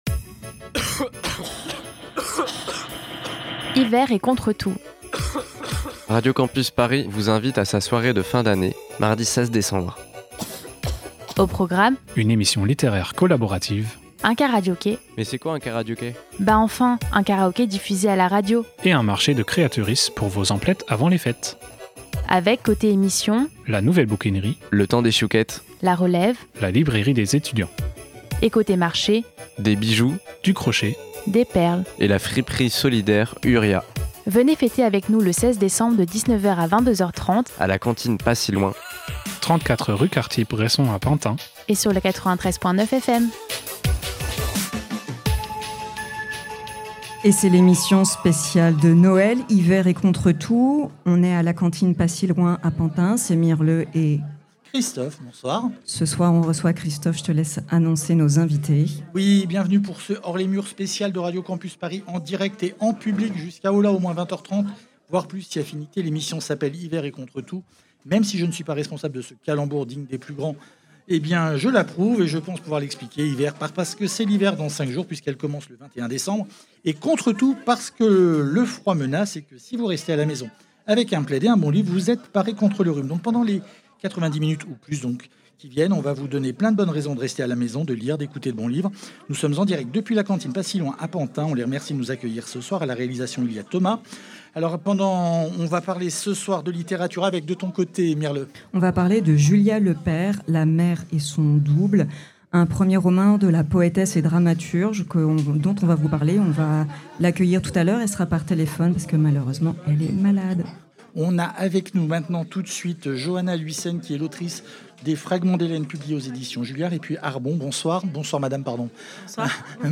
La relève a eu l'insigne honneur de participer au Hors les murs d'hiver de Radio Campus Paris en direct depuis la cantine pas si loin à Pantin (un super endroit, une super découverte pour nous)